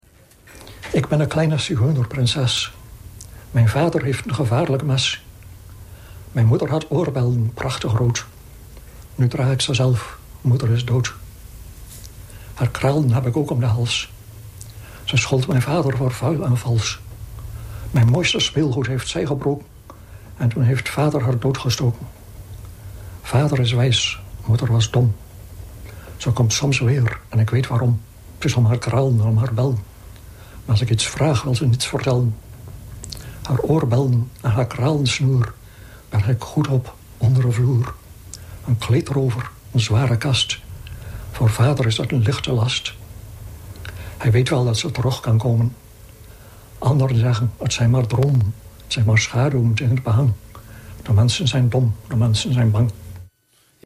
Maar datzelfde interview had ook even de stem van de dichter zelf. En die diep-Groninger voordracht van Hendrik de Vries vind ik geweldig, sinds dat ene videofragment uit ‘De Dode dichters almanak’.